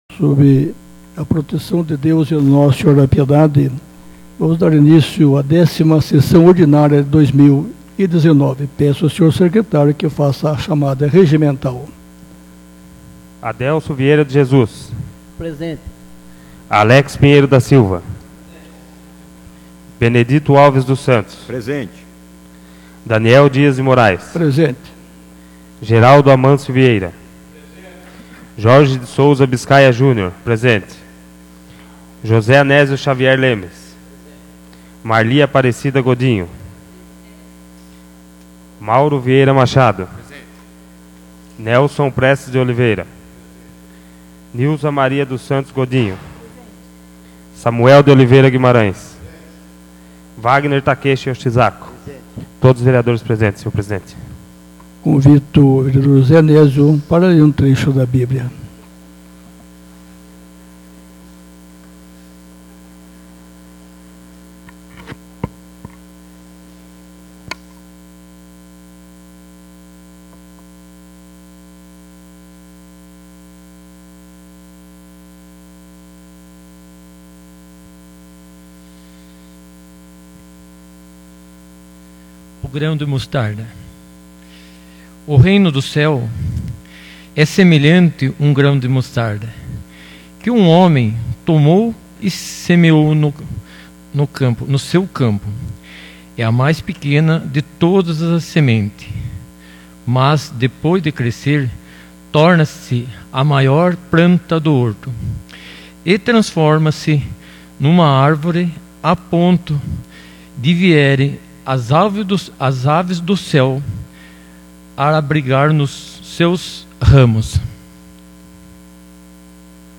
10ª Sessão Ordinária de 2019